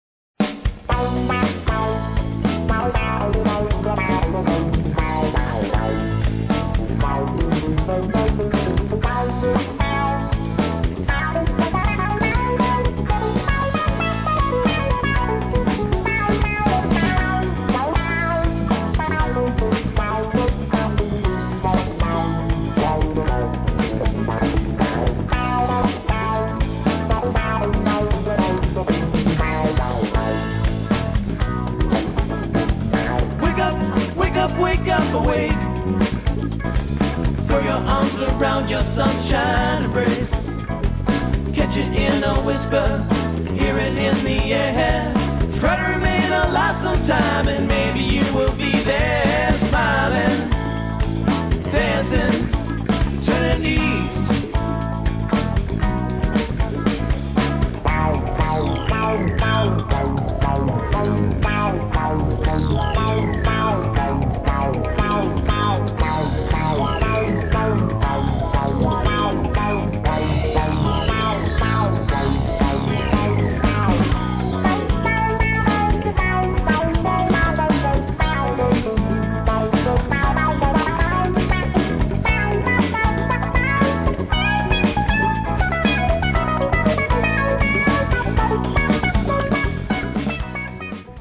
会場のピースなバイブレーションに包まれながら、独特なメロディーと心地よく重なりあうボーカル。